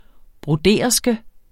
Udtale [ bʁoˈdeˀʌsgə ]